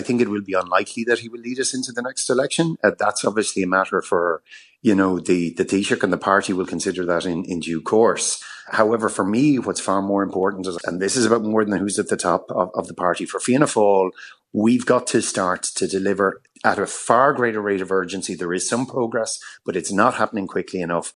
Fianna Fail TD Malcolm Byrne says Micheal Martin can survive the next few weeks but isn’t sure beyond that………………..